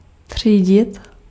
Ääntäminen
Synonyymit řadit zařadit klasifikovat Ääntäminen : IPA: [tr̝̊iːɟɪt] Haettu sana löytyi näillä lähdekielillä: tšekki Käännös 1. clasificar Esimerkit třídit odpad, trier les ordures, faire le tri sélectif des déchets.